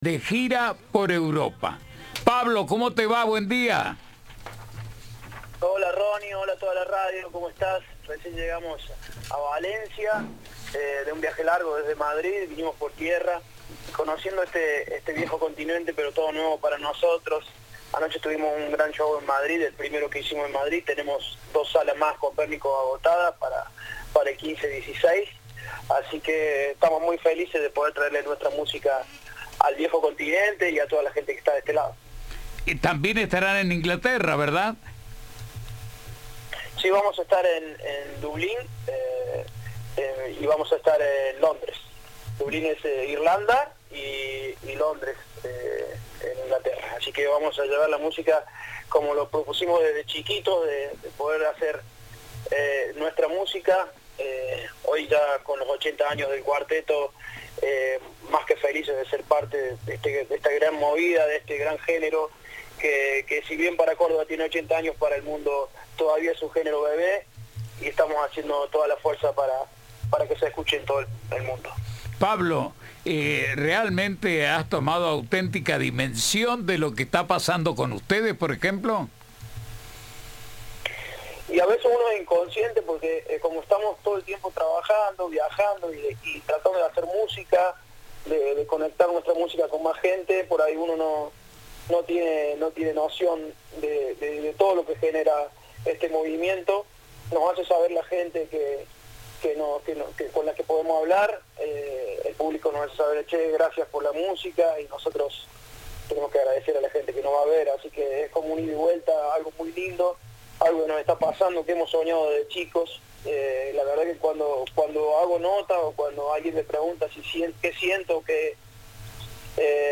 En una entrevista con Cadena 3 el líder de la banda de cuarteto cordobesa compartió su emoción y agradecimiento por la gira europea que emprenden, con fechas agotadas en lugares como Barcelona o Valencia.
Entrevista